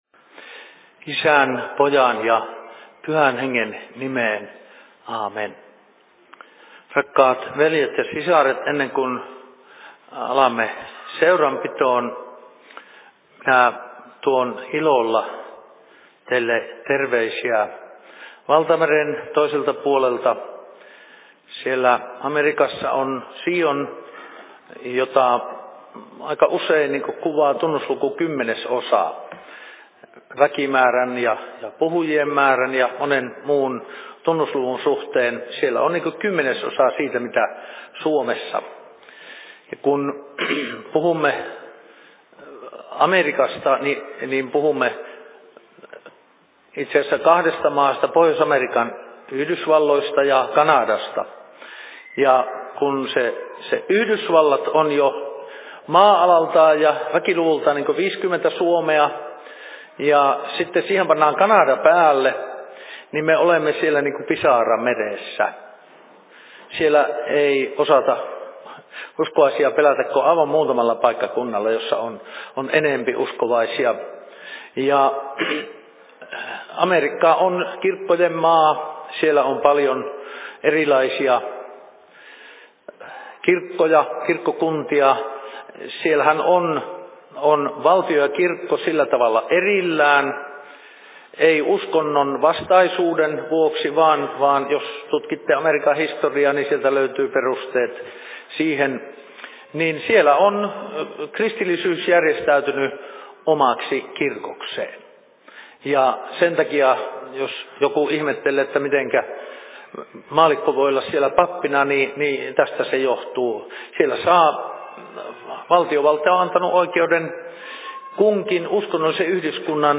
Seurapuhe Jyväskylän RY:llä 09.01.2019 19.04
Paikka: Rauhanyhdistys Jyväskylä